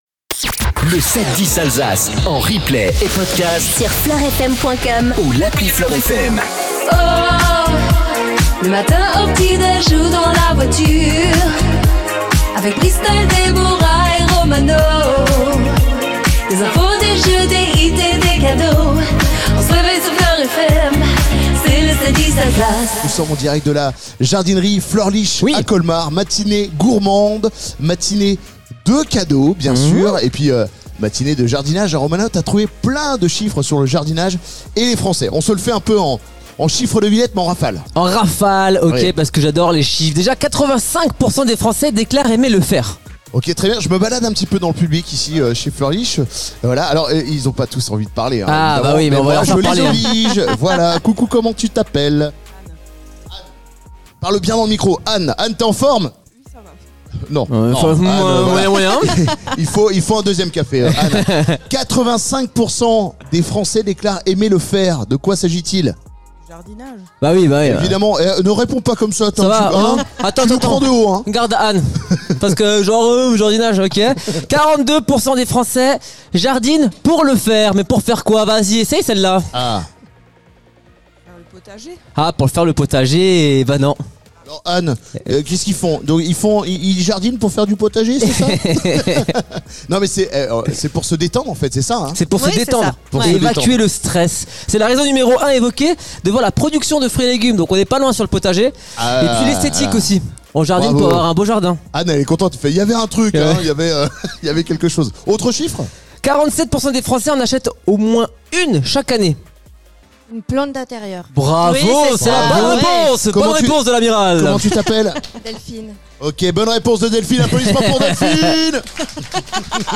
710 ALSACE FLORFM PODCAST MORNING ALSACE M2 COLOR FLOR FM Vendredi 25 avril 0:00 34 min 46 sec 25 avril 2025 - 34 min 46 sec LE 7-10 DU 25 AVRIL Retrouvez les meilleurs moments du 7-10 Alsace avec M2 Color , votre façadier dans le Haut-Rhin, en direct de chez Fleurs Lisch à Colmar .